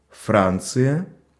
Ääntäminen
France: IPA: [fʁɒ̃ːs]